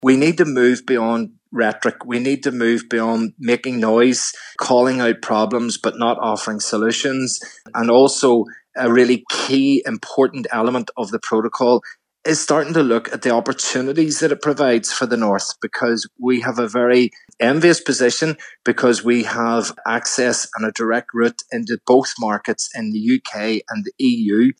Committee chair and SDLP MLA, Colin McGrath, hopes Mr Frost presents practical solutions today: